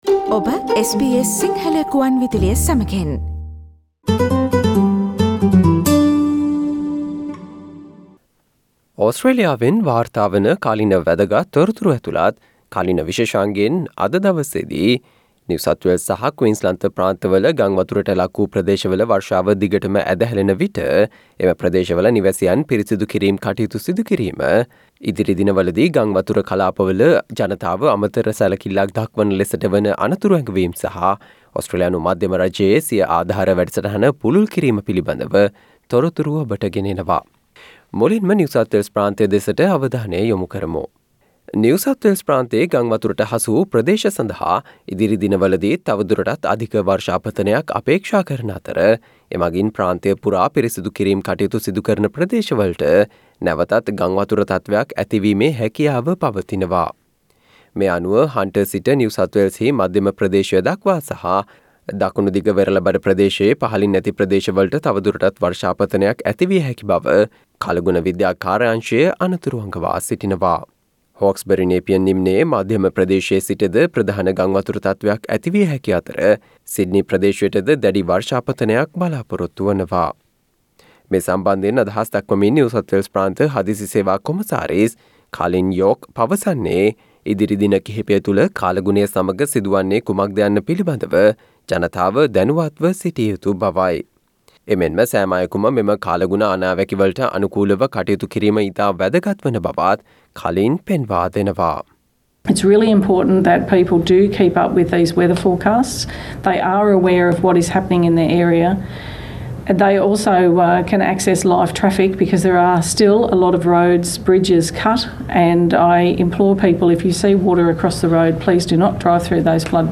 නිව් සවුත් වේල්ස් ප්‍රාන්තයේ ගංවතුරට හසු වූ ප්‍රදේශ ඉදිරි දිනවලදීත් තවදුරටත් අධික වර්ෂාපතනයක් අපේක්ෂා කරන අතර ඒ සම්බන්ධයෙන් වන අනතුරු ඇඟවීම් පිළිබඳ නවතම තොරතුරු රැගත් මාර්තු 07 වන දා සඳුදා ප්‍රචාරය වූ SBS සිංහල සේවයේ කාලීන තොරතුරු විශේෂාංගයට සවන්දෙන්න.